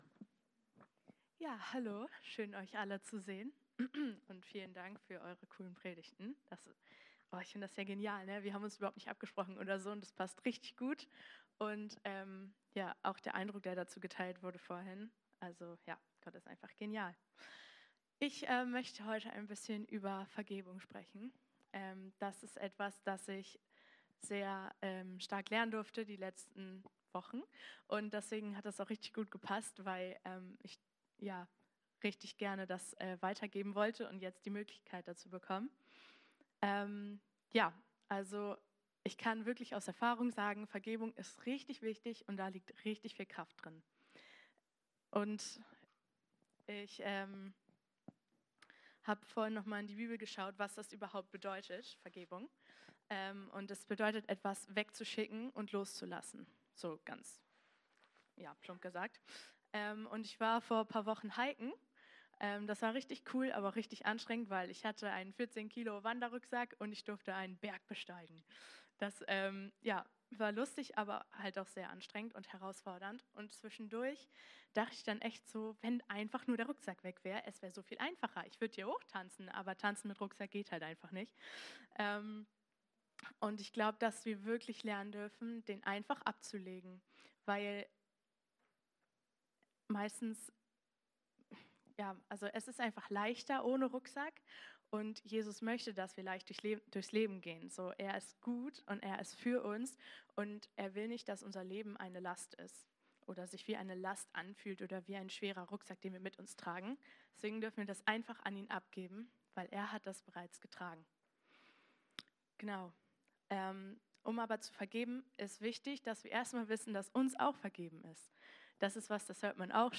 Eine predigt aus der predigtreihe "Goldstückepredigt."